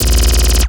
LASRGun_Alien Handgun Burst_02_SFRMS_SCIWPNS.wav